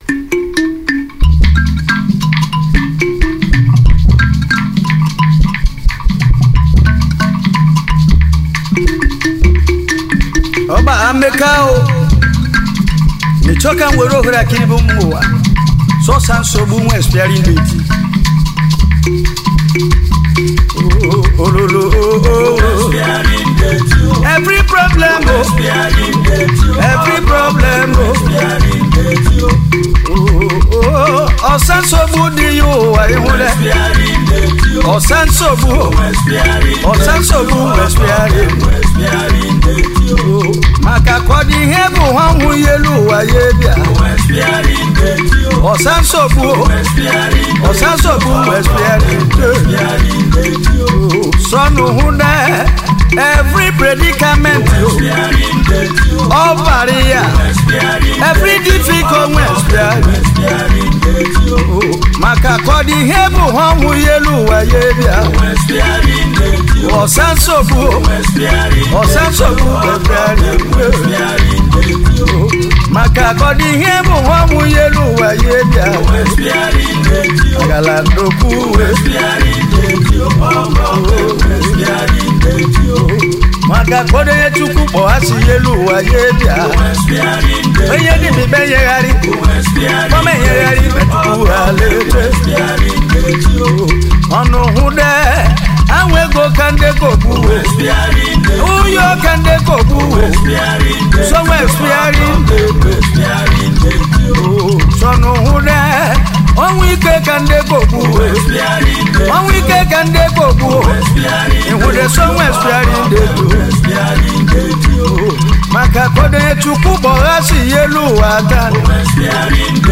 Cultural Songs